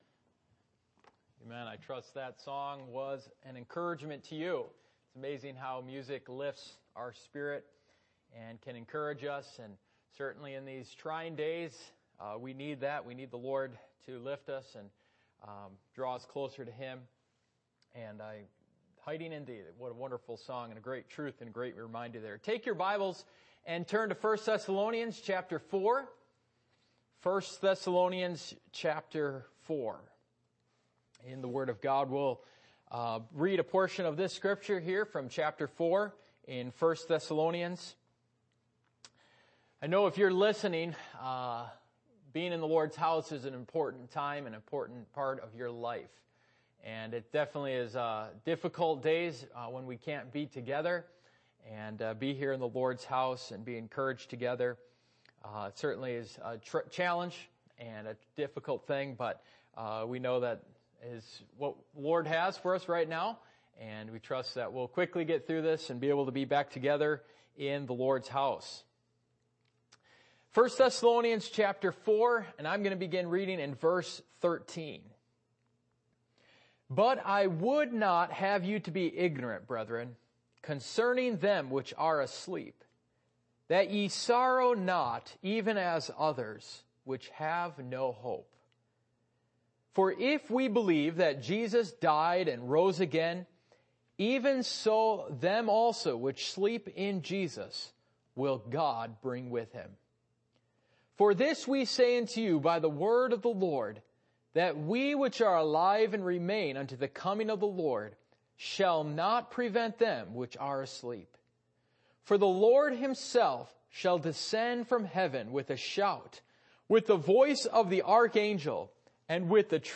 Passage: 1 Thessalonians 4:13-17 Service Type: Midweek Meeting